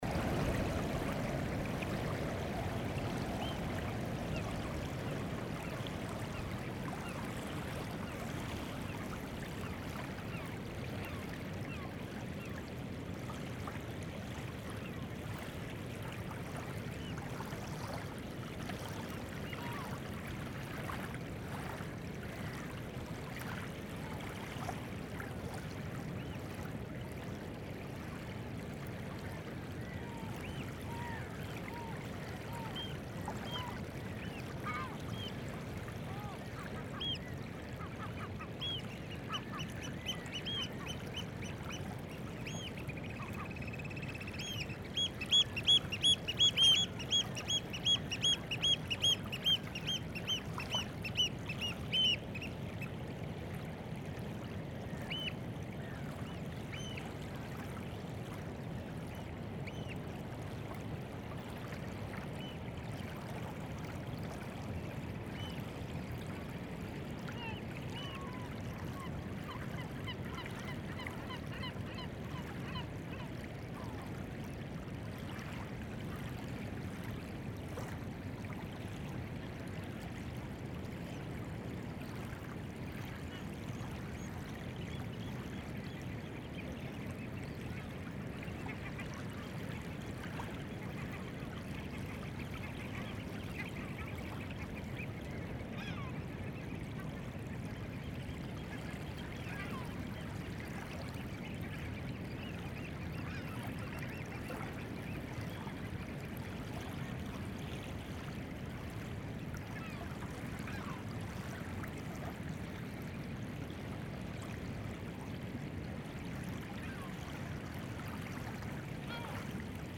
getijdengeul bij Rottumerplaat
wad_-_14_-_getijdengeul_rottumerplaat1.mp3